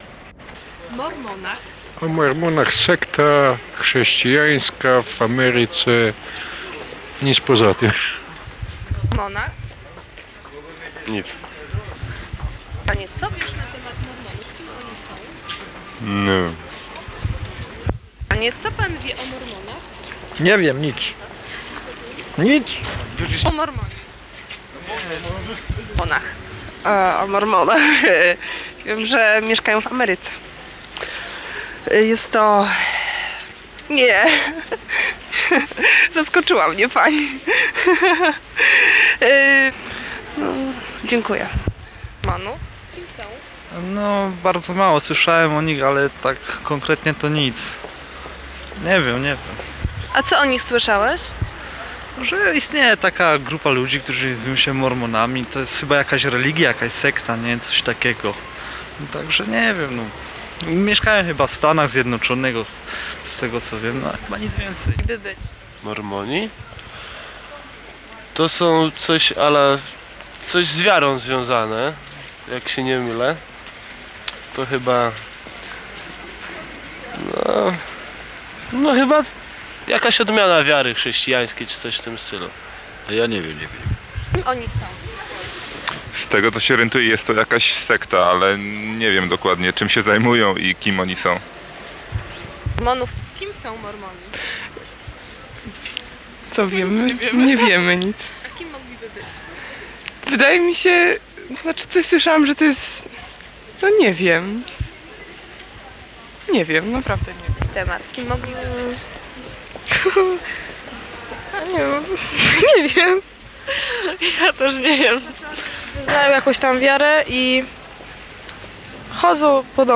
Sonda - „Kim są Mormoni”?